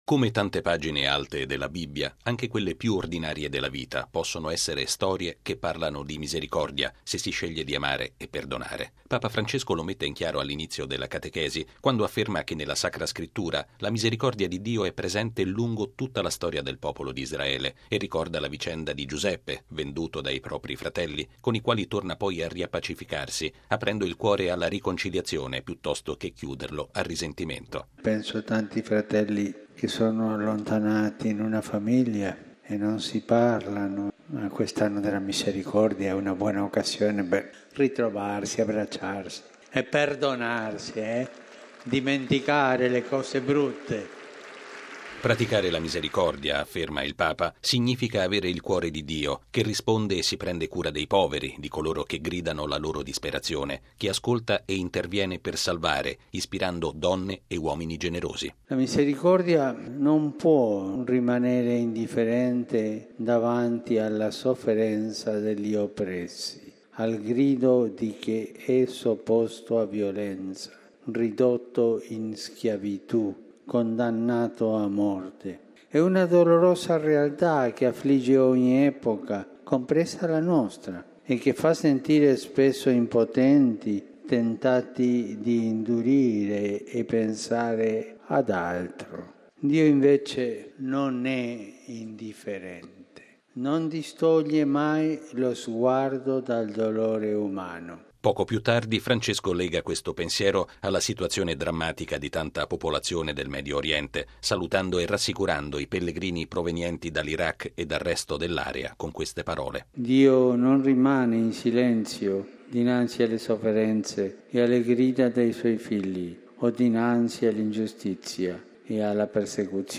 È una delle affermazioni centrali della catechesi di Papa Francesco all’udienza generale svoltasi in Piazza San Pietro. Il Papa ha invitato anche alla pace in famiglia: il Giubileo, ha detto, “è una buona occasione” per “perdonarsi”. Il servizio